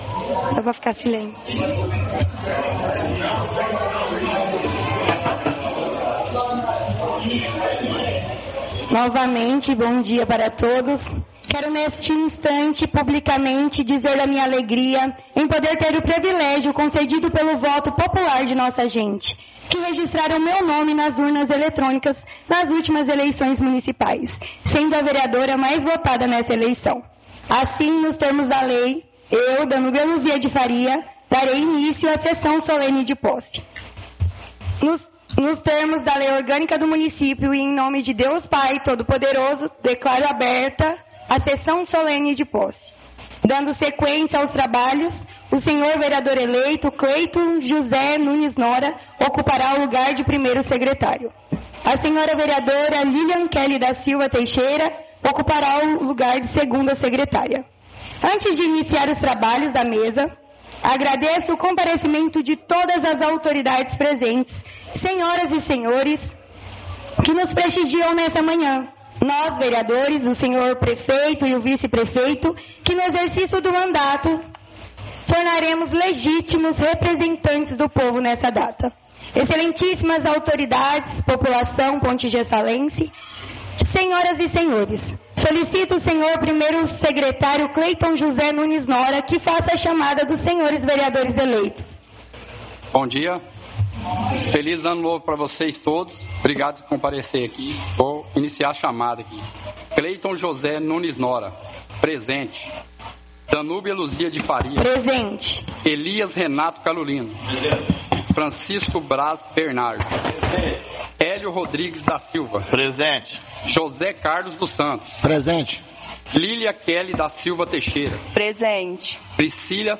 Áudio da 1ª Sessão Solene de Posse – 01/01/2025